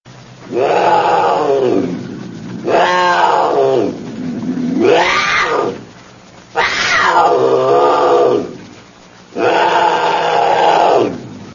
جلوه های صوتی
دانلود صدای پلنگ از ساعد نیوز با لینک مستقیم و کیفیت بالا